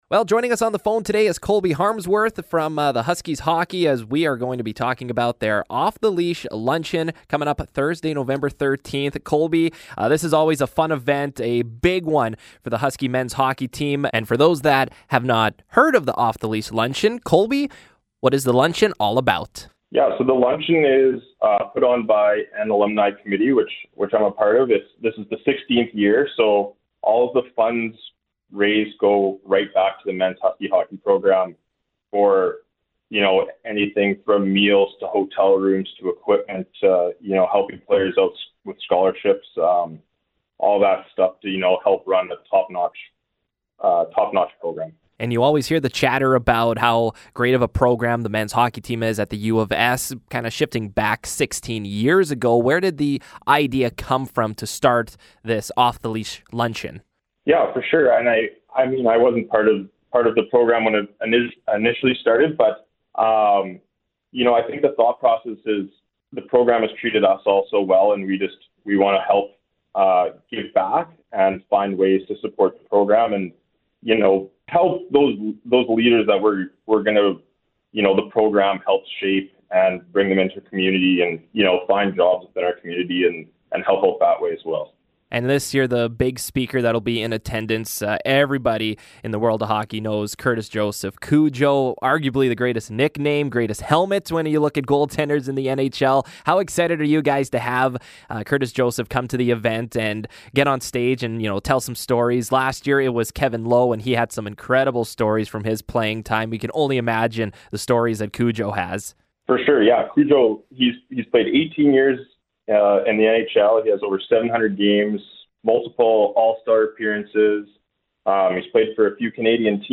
over the phone to discuss what the Luncheon is all about and what to expect from “Cujo”!